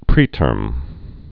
(prētûrm, prē-tûrm)